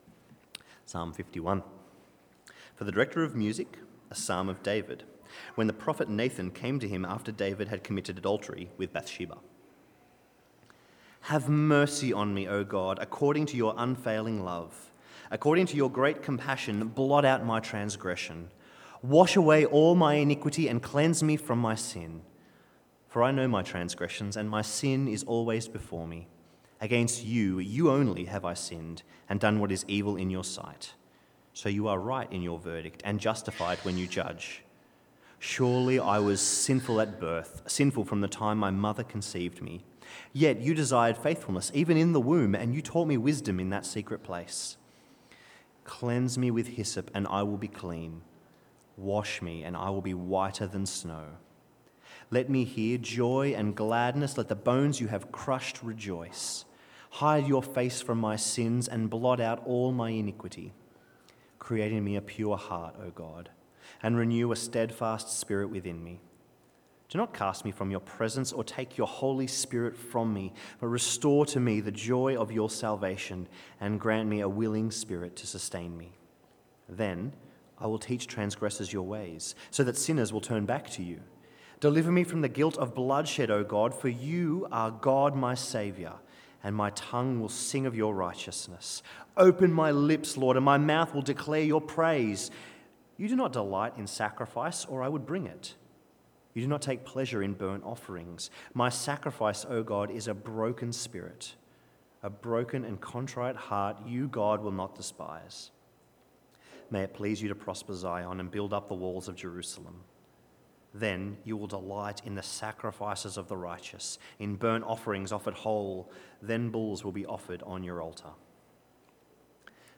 Service Type: Rosemeadow PM